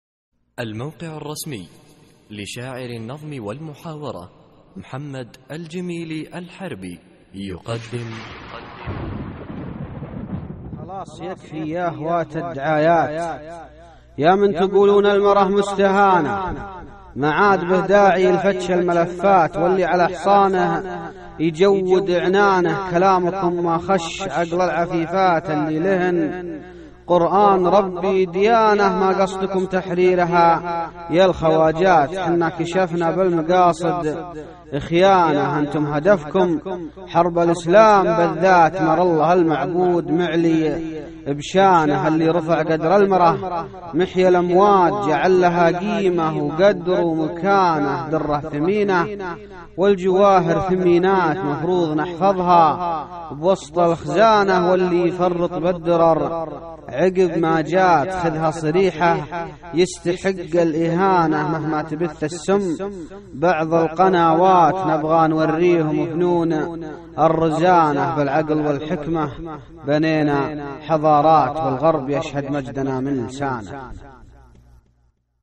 القصـائــد الصوتية
اسم القصيدة : بالمقاصد خيانة ~ إلقاء